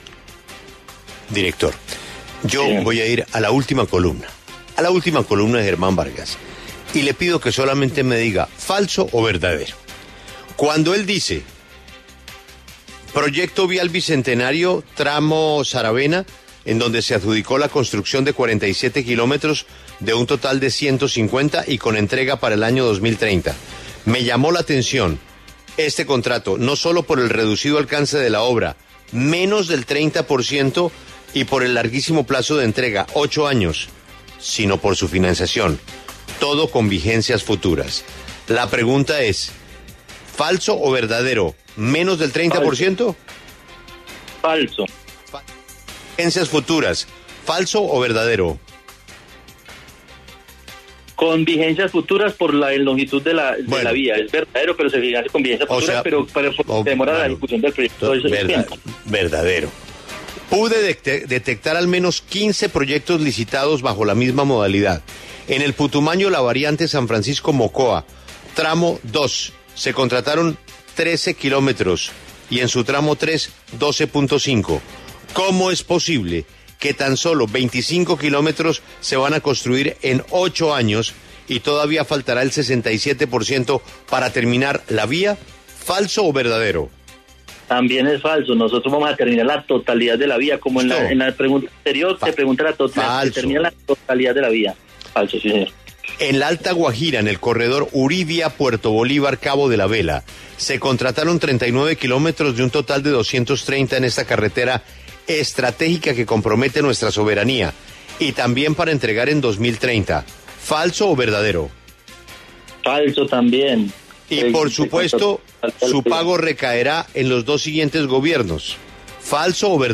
En diálogo con La W, Juan Esteban Gil, director del Instituto Nacional de Vías (Invías), respondió a las críticas del ex vicepresidente sobre los diferentes proyectos de infraestructura que se adelantan en el país.
Lo invitamos a que escuche la entrevista completa a Juan Esteban Gil, director del Invías, en el siguiente player: